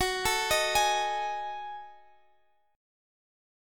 Gbm6add9 Chord
Listen to Gbm6add9 strummed